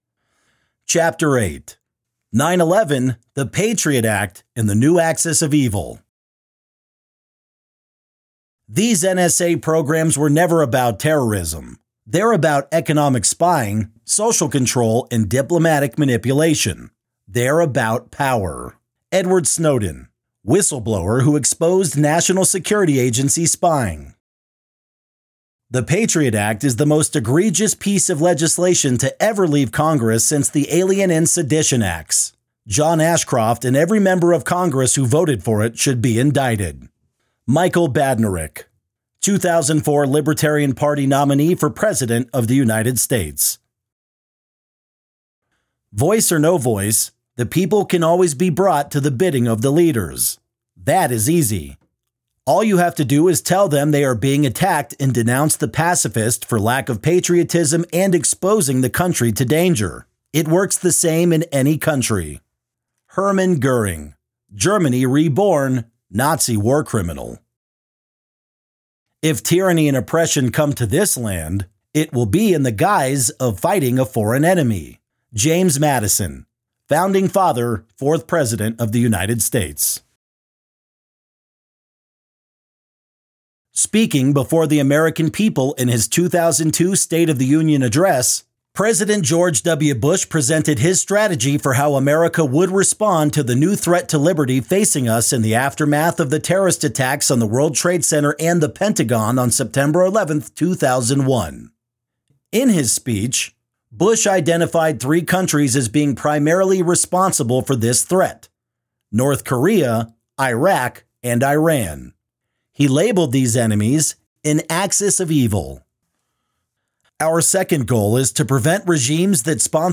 Chapter-8-audio-book.mp3